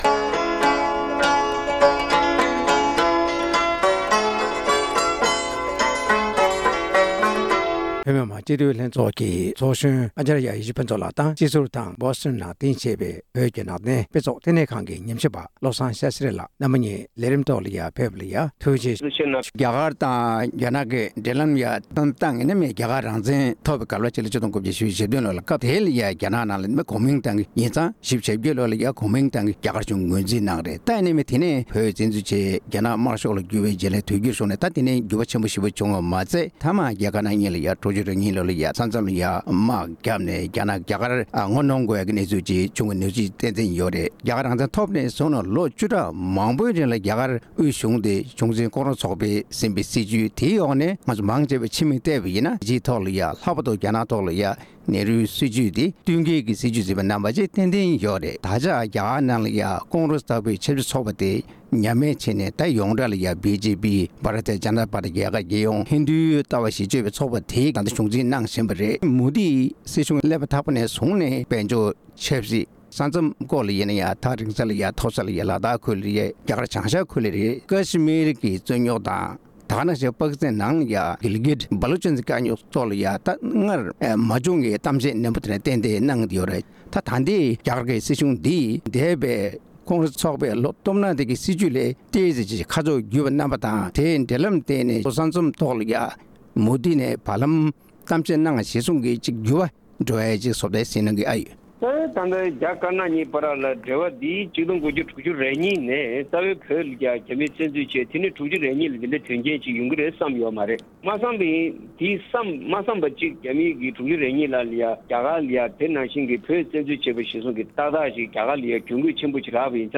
རྒྱ་གར་གཞུང་གིས་རྒྱ་ནག་ཐོག་འཛིན་པའི་སྲིད་ཇུས་སྤྱི་དང་བོད་ཀྱི་རྩ་དོན་སྐོར་གླེང་མོལ་གནང་བ།